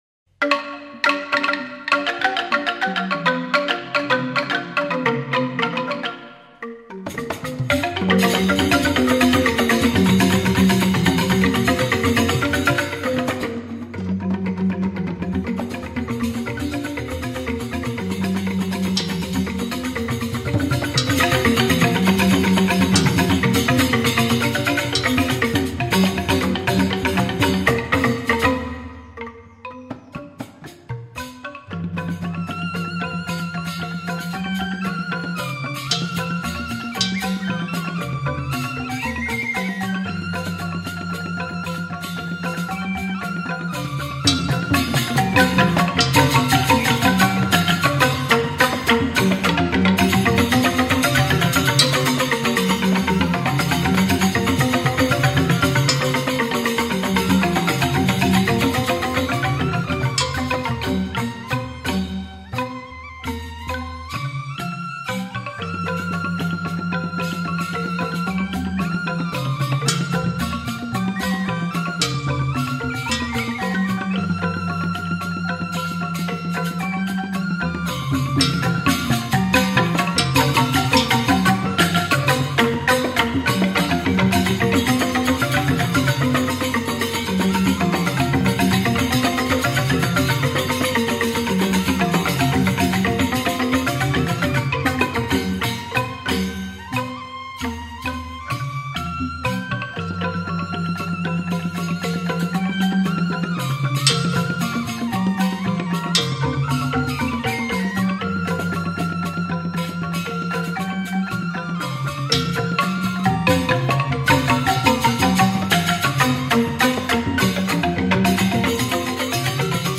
最原始的羅曼蒂克音樂